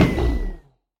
mob / enderdragon / hit1.ogg
hit1.ogg